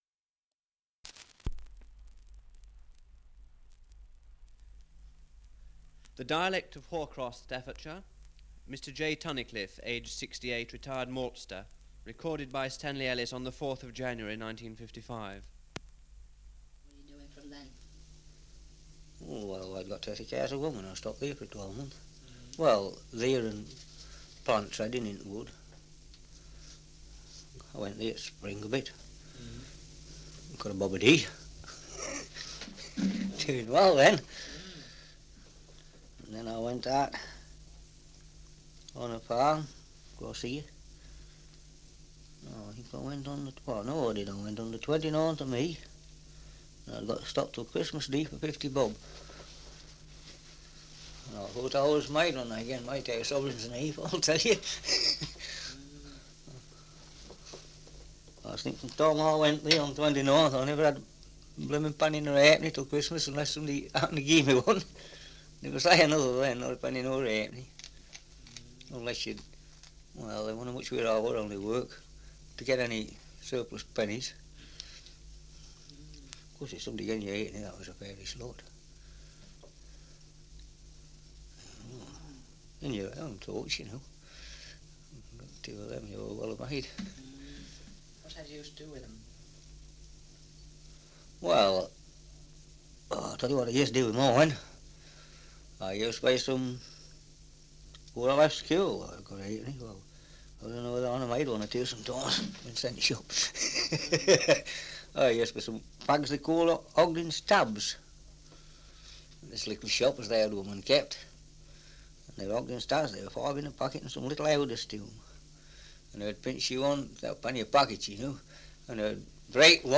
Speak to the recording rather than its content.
Survey of English Dialects recording in Hoar Cross, Staffordshire 78 r.p.m., cellulose nitrate on aluminium